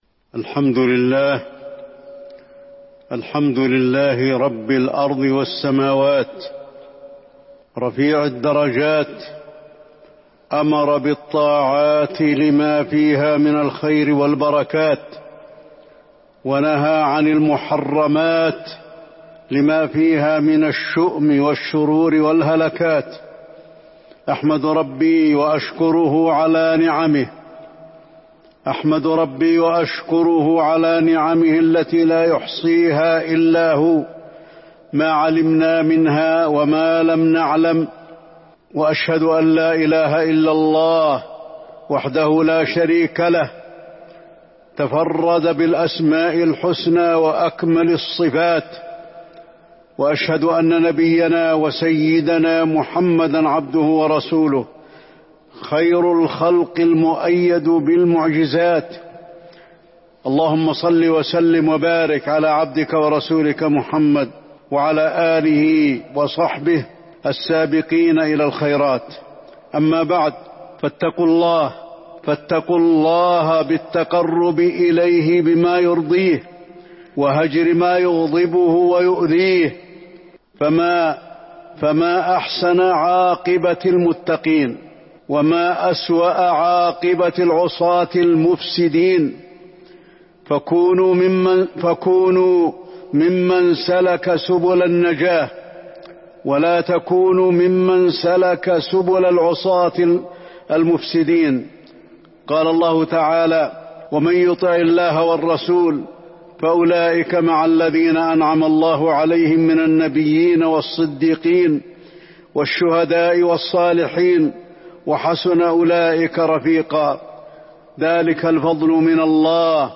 تاريخ النشر ٢٢ صفر ١٤٤٢ هـ المكان: المسجد النبوي الشيخ: فضيلة الشيخ د. علي بن عبدالرحمن الحذيفي فضيلة الشيخ د. علي بن عبدالرحمن الحذيفي الكون حكم وأحكام